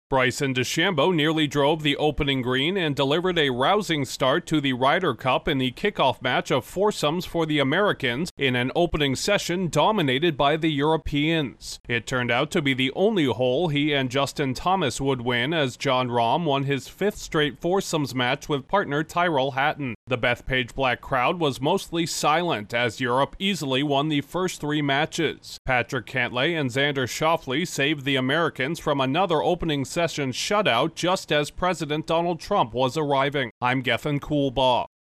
The Americans got off to a rough start at the Ryder Cup in New York. Correspondent